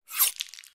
scalpel2.ogg